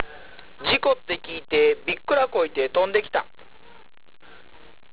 方言